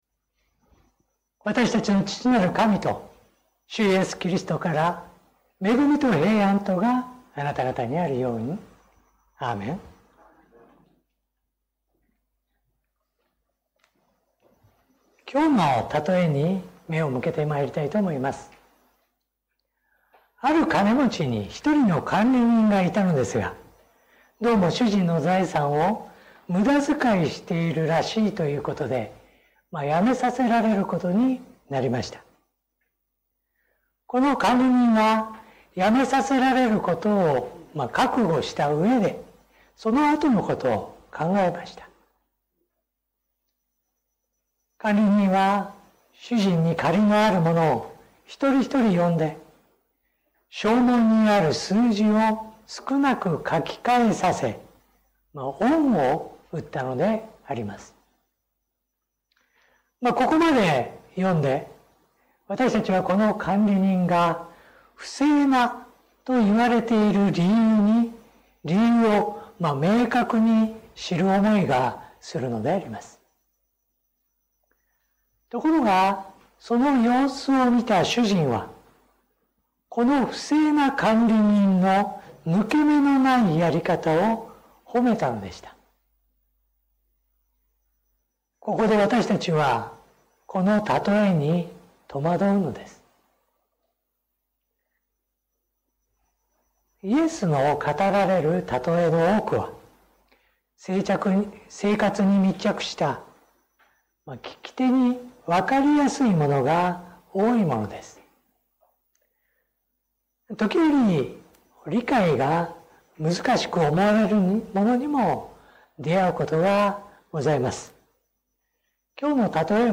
説教音声 最近の投稿 2026年3月22日 礼拝・四旬節第5主日 3月22日 「死んでも生きる？」